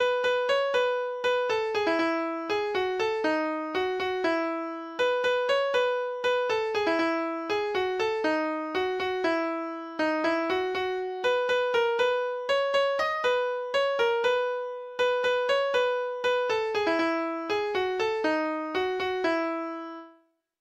Folketone frå Telemark
Lytt til data-generert lydfil